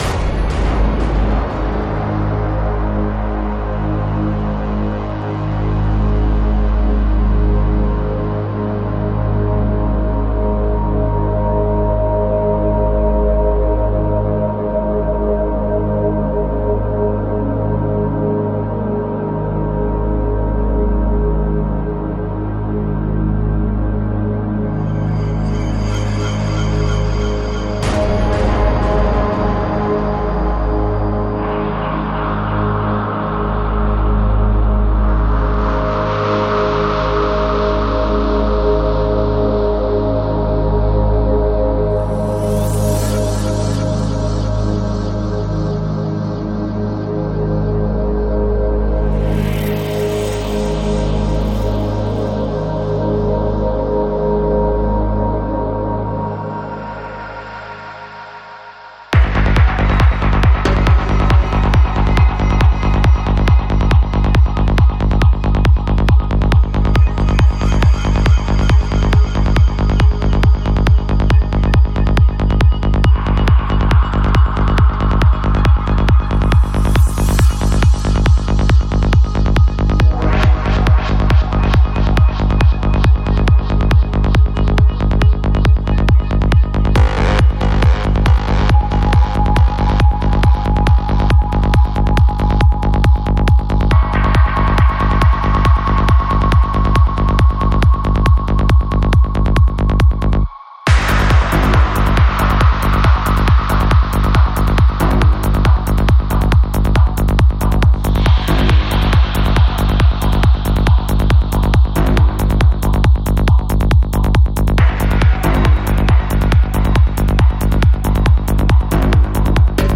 Psy-Trance